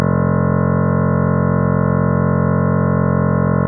7.2 Inverse DFT: synthesis of a piano note
To my ear, it sounds very similar to the original piano note. The main difference is that the characteristic percussive start of the original piano recording is not there, and most notably, the amplitude does not decay over the course of the synthesized clip.
synthesized_piano.wav